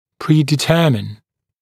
[ˌpriːdɪ’tɜːmɪn][ˌпри:ди’тё:мин]заранее определять, предопределять